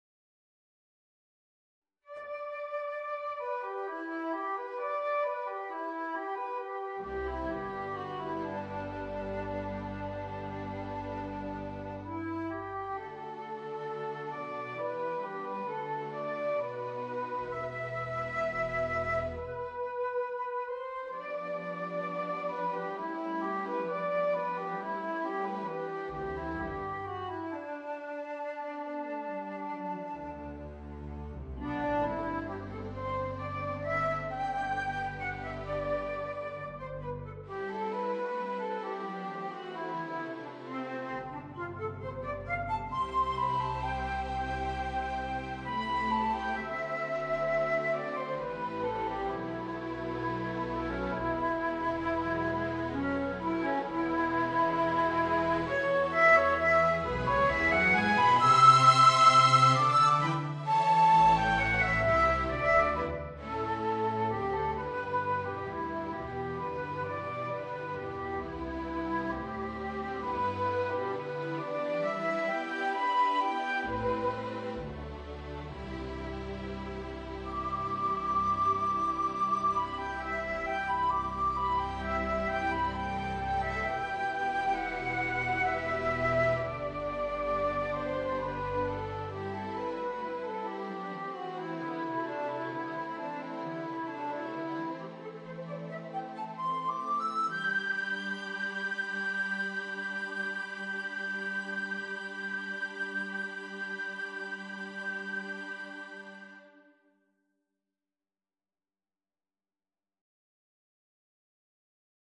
Voicing: Viola and String Orchestra